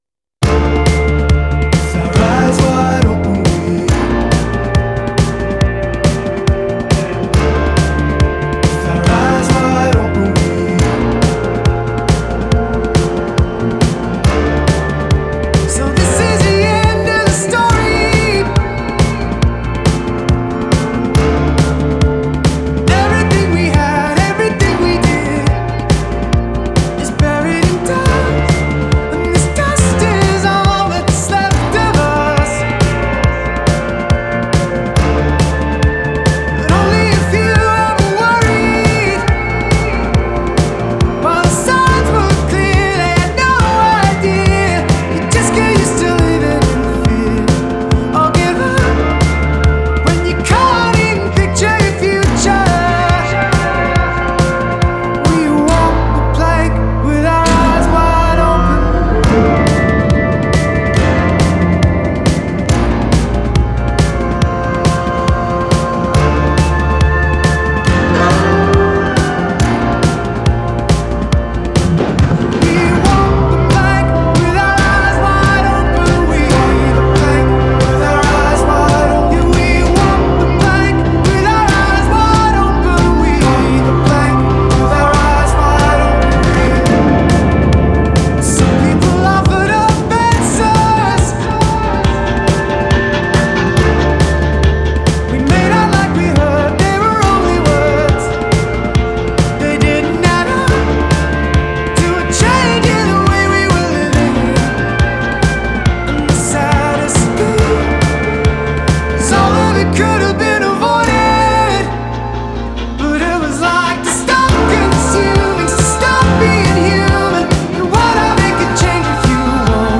Genre: Indie Rock / Electronic / Alternative